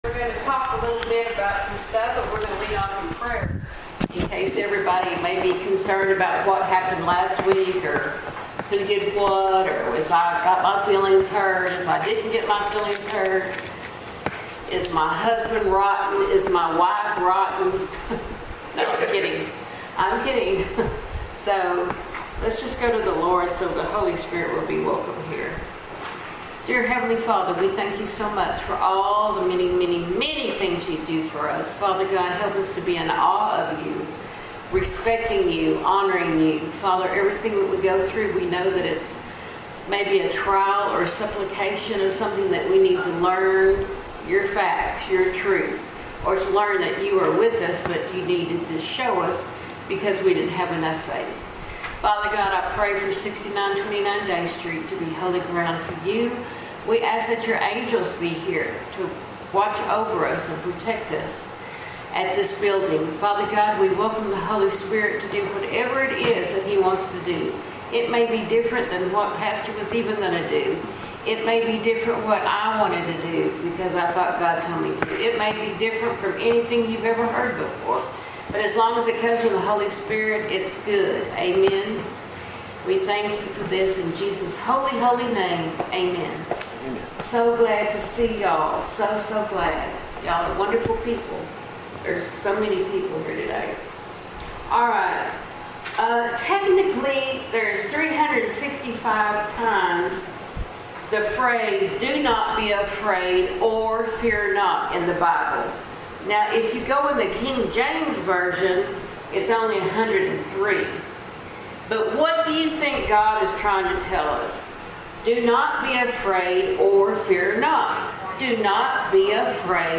Bartimaeus Baptist Temple Sunday School